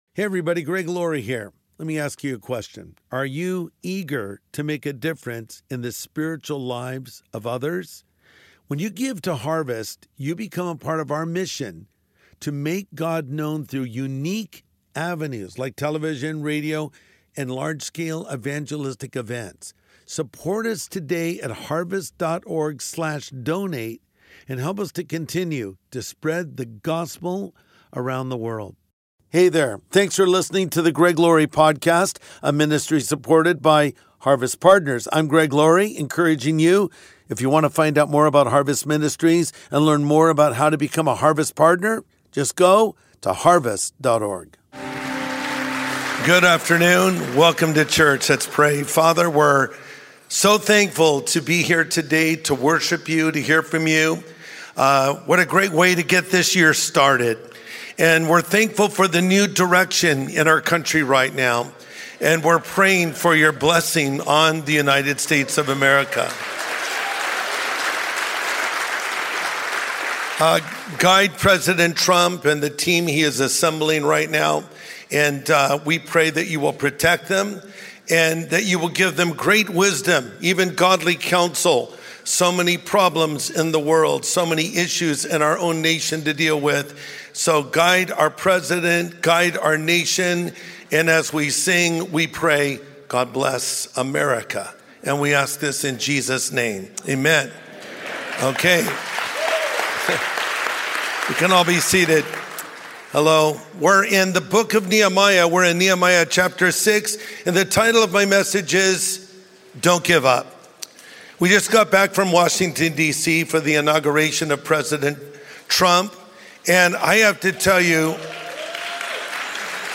Pastor Greg Laurie shares on how to stay focused on what God has called us to do, how to overcome distractions, and how to finish our race strong.